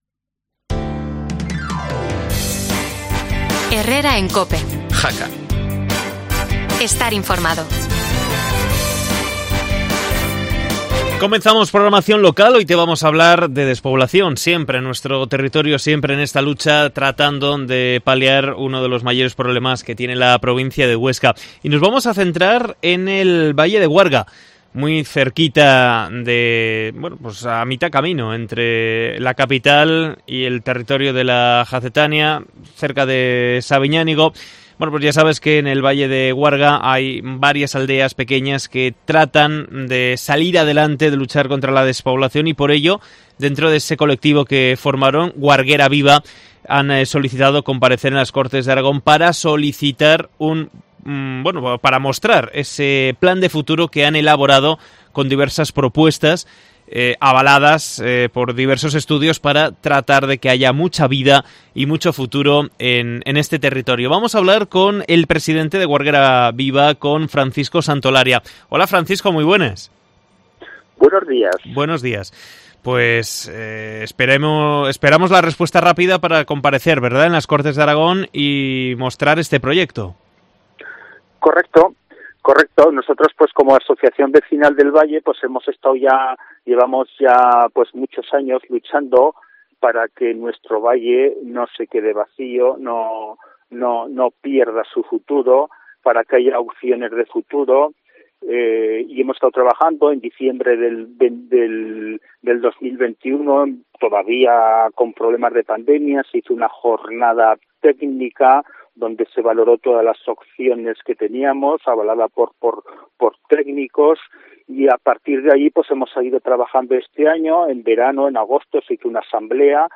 Entrevista a la Asociación Guarguera Viva